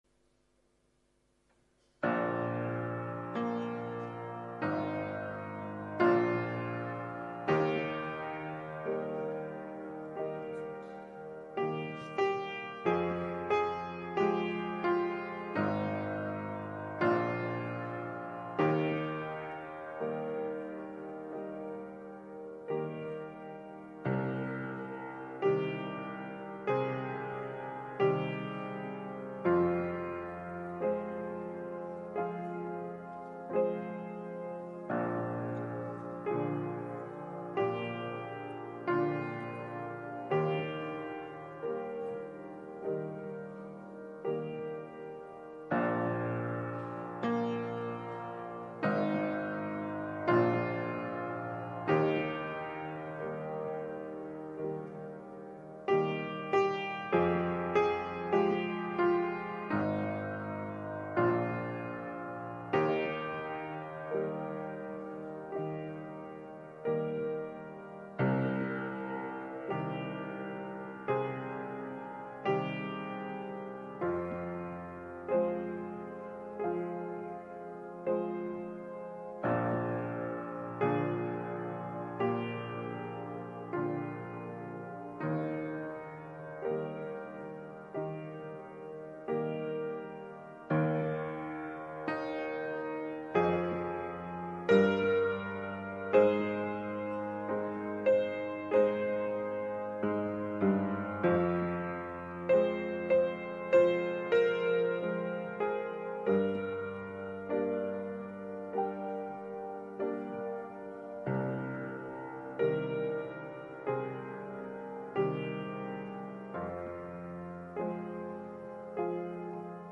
团契 | 北京基督教会海淀堂